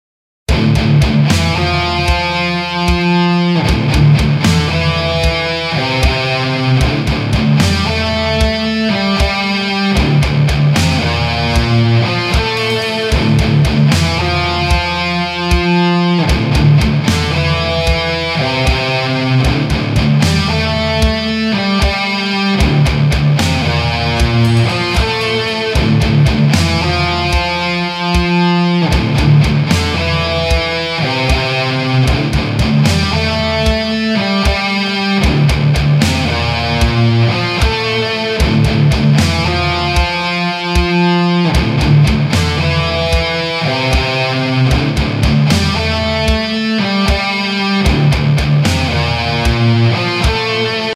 Скрипки нет. Ударные с гитарой присутствуют.
Три гитары и барабаны, скрипки нет